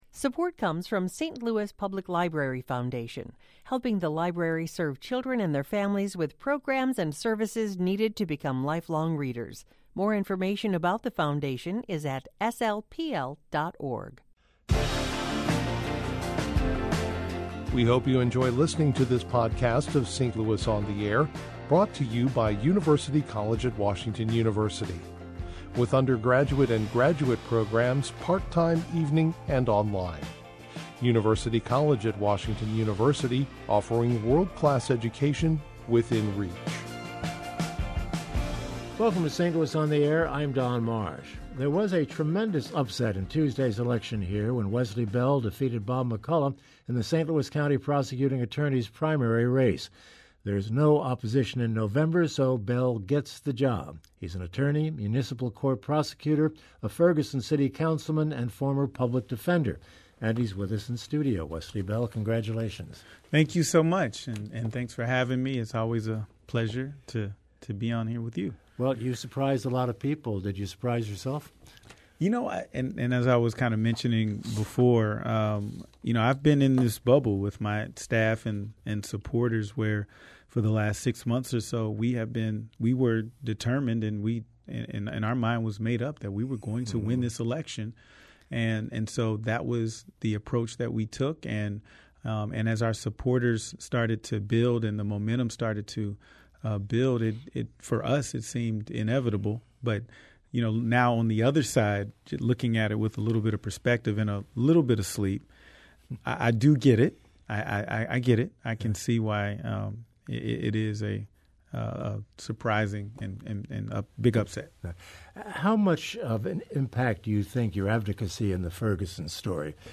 ‘The fight must go on’: A conversation with St. Louis County’s next prosecutor, Wesley Bell